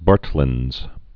(bärtl-ĭnz, -thə-lĭnz)